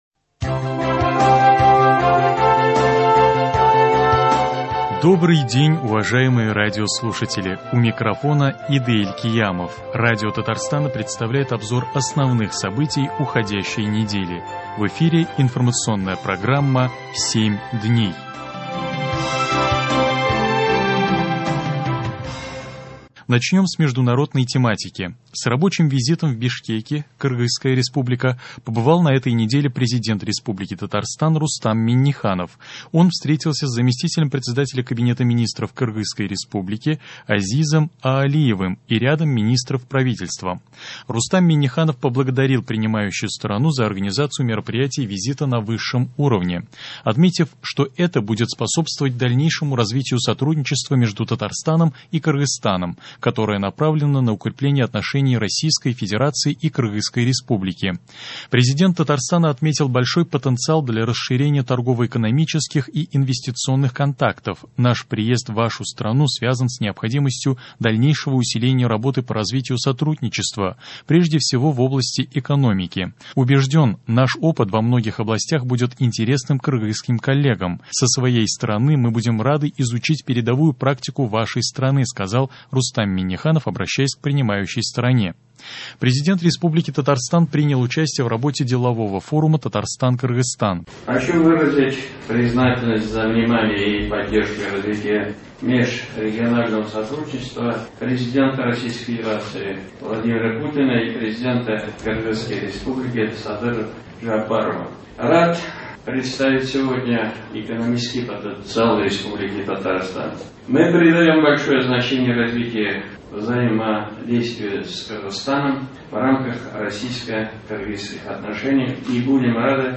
Обзор событий недели. С рабочим визитом в Бишкеке (Кыргызская Республика) побывал на этой неделе Президент Республики Татарстан Рустам Минниханов.